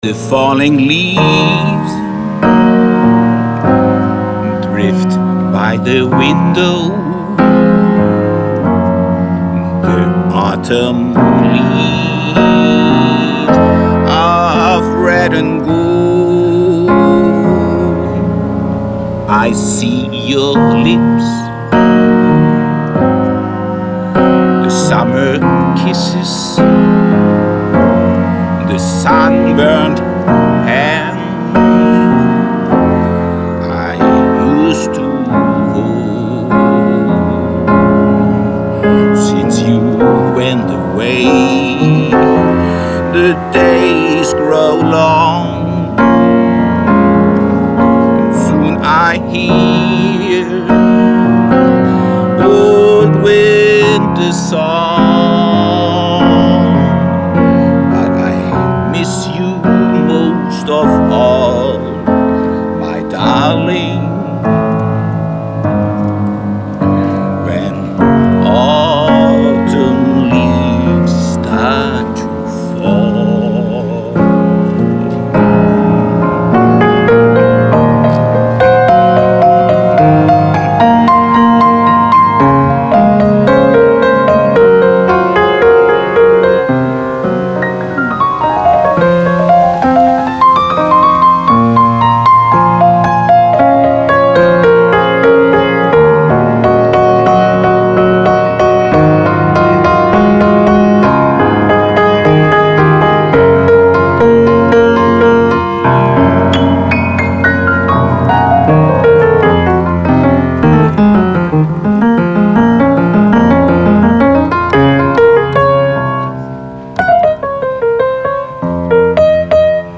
Waldpflanzengarten: